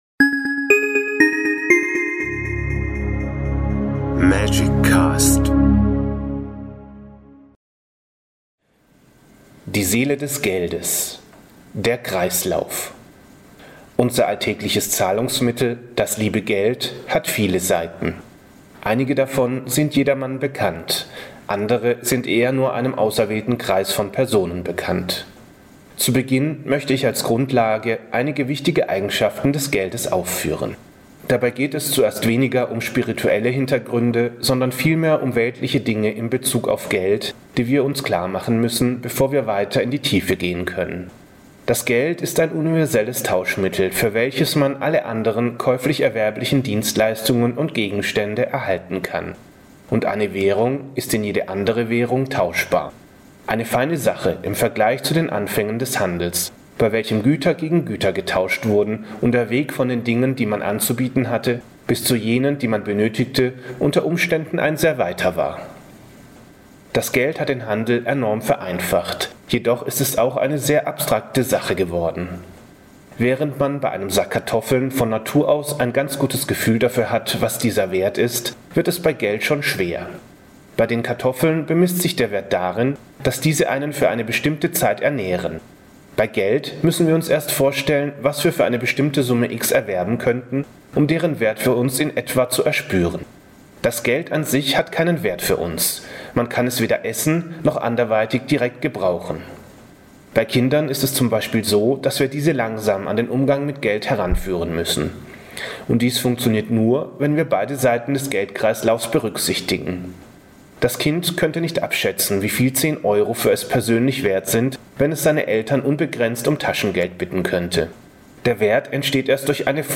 Dies ist der erste Teil des Vortrages, der Ihnen eine neue Art zeigt unser alltägliches Zahlungsmittel zu betrachten und dementsprechend damit umzugehen.